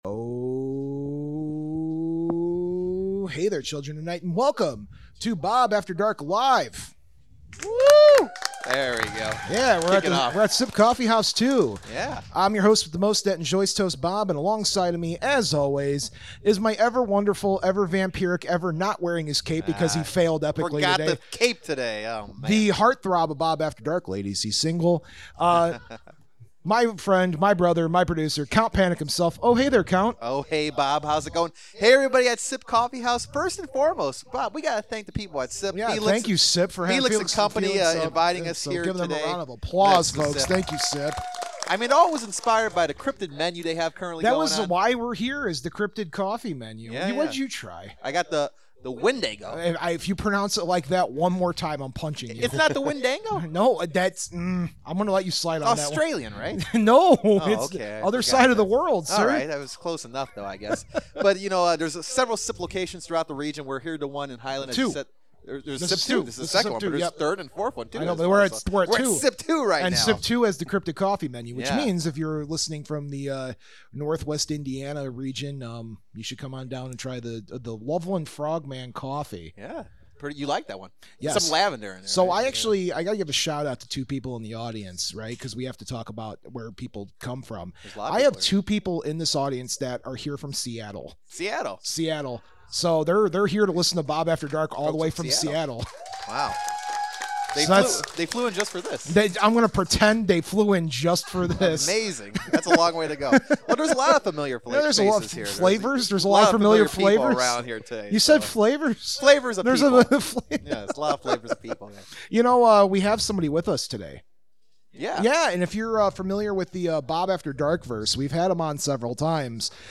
Thanks to SIP Coffee House 2, Bob After Dark did a live episode! We had a great crowd, and talked about some really cool stories. WJOB Studios, Little Red School House, and Old Lake County Jail in Crown Point Indiana. The audio isn't perfect, so bare with us.